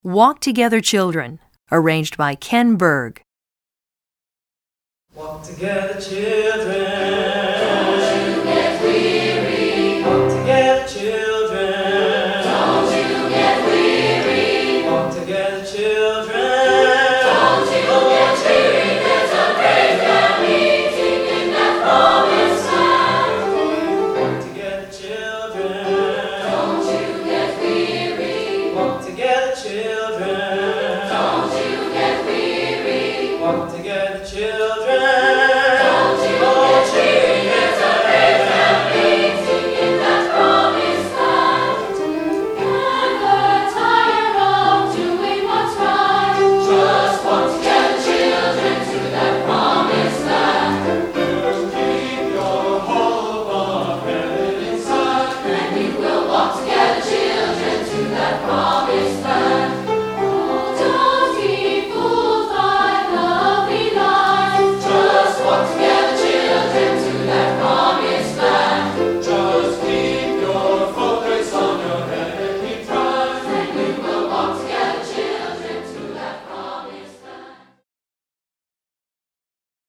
Composer: Spirituals
Voicing: 3-Part Mixed